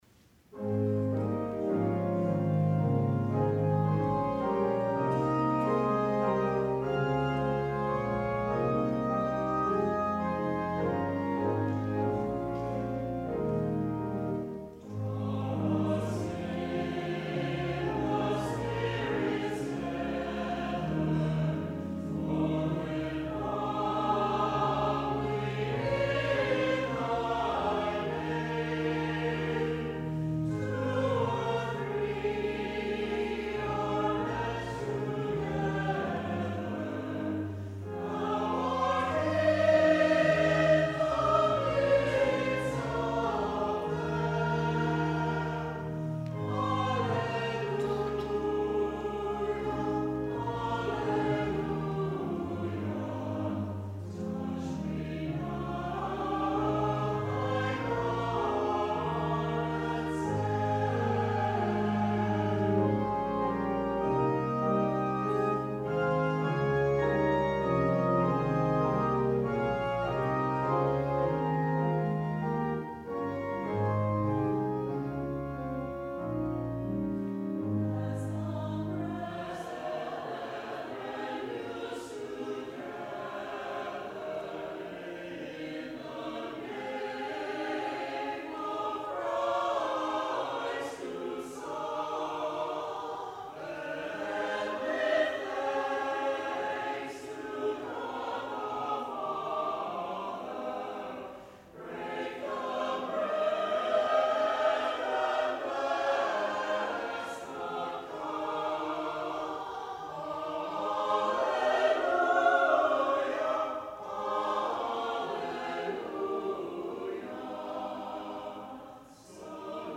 Chancel Choir
organ